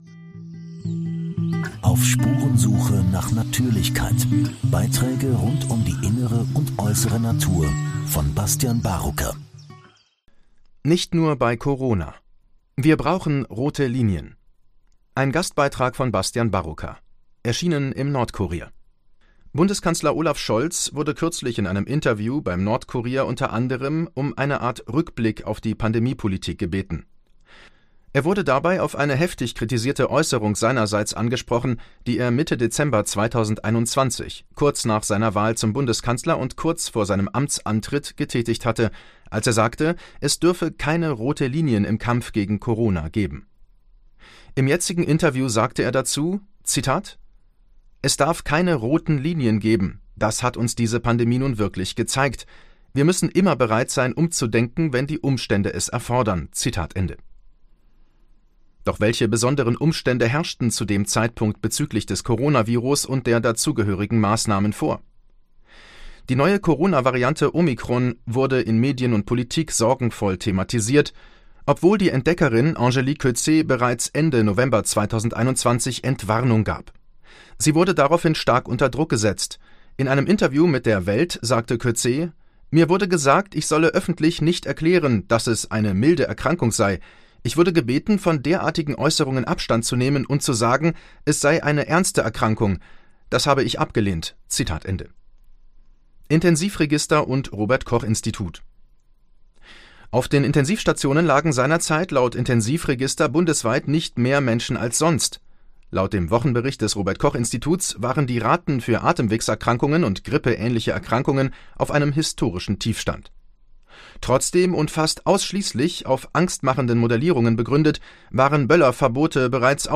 Gastkommentar im Nordkurier